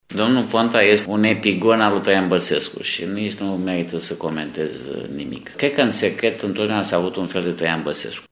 Declaraţia aparține vicepreședintelui PNL Nini Săpunaru și a fost făcută sâmbătă la Tîrgu-Mureş.